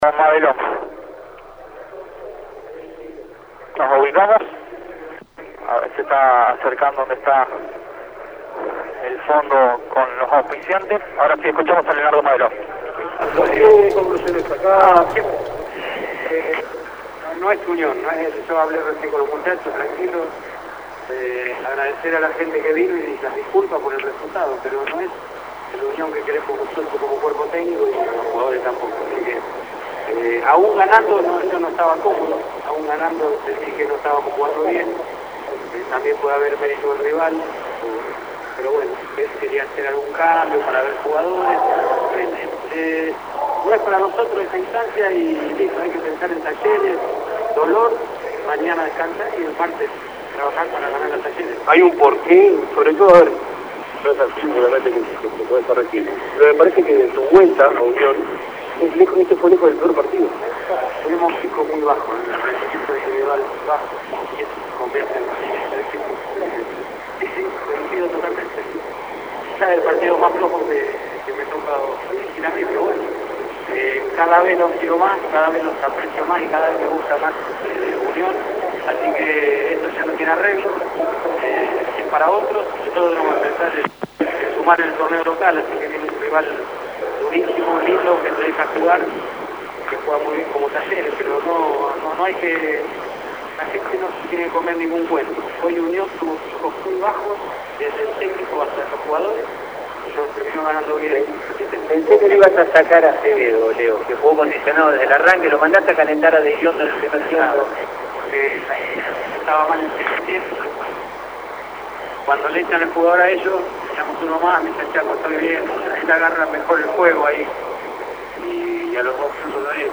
• Leonardo Madelón luego del 2 a 1 frente a Sarmiento.